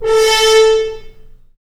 Index of /90_sSampleCDs/Roland L-CDX-03 Disk 2/BRS_F.Horns FX+/BRS_FHns Mutes